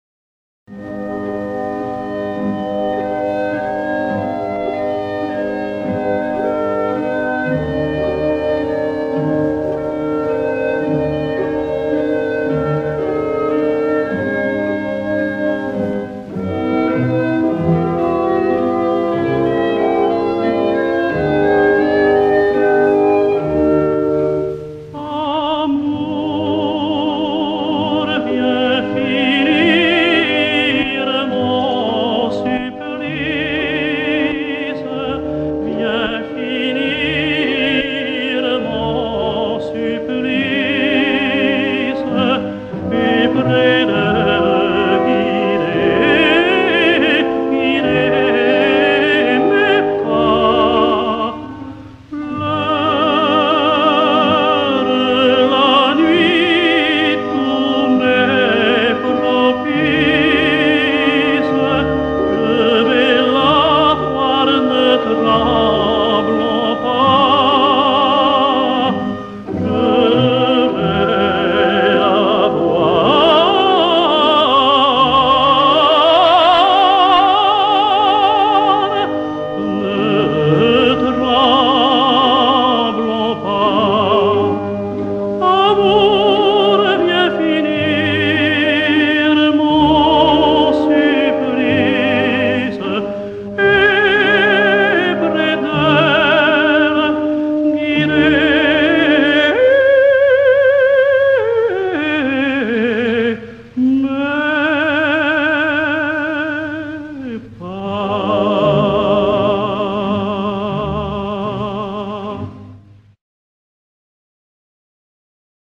André Mallabrera singsLe domino noir: